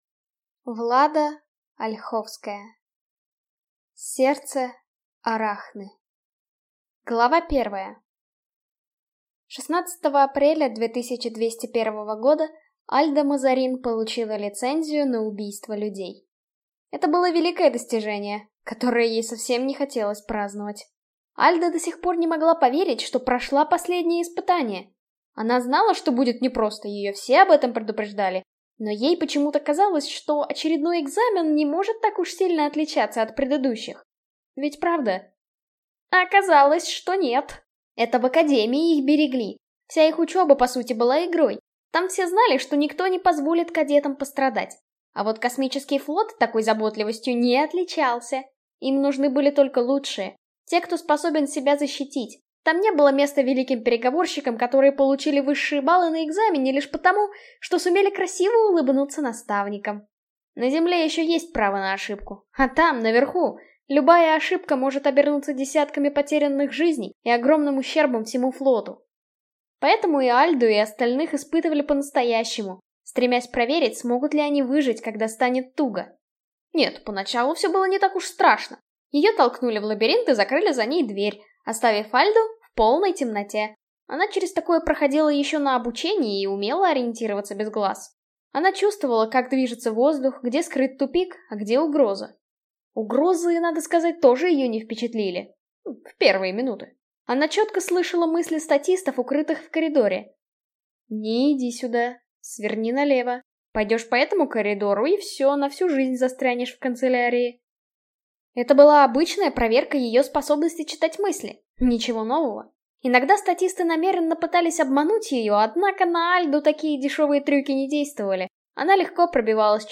Аудиокнига Сердце Арахны | Библиотека аудиокниг